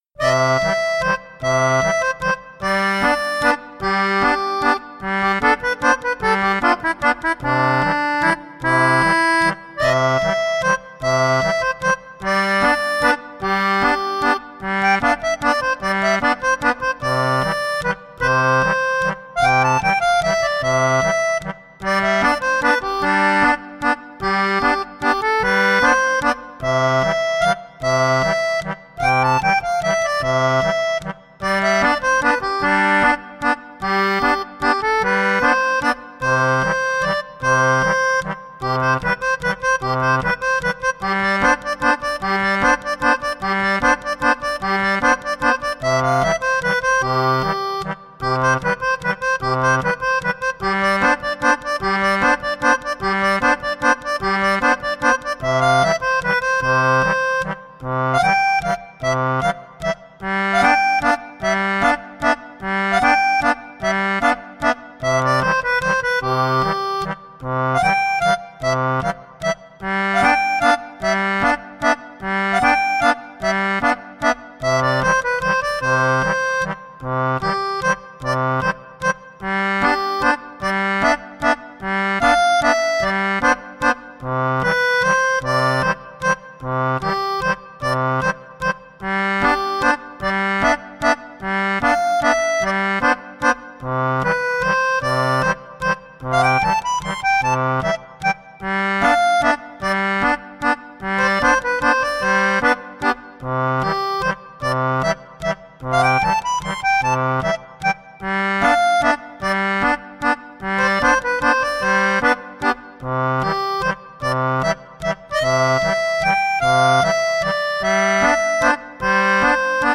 アコーディオンの軽快な曲です。【BPM150】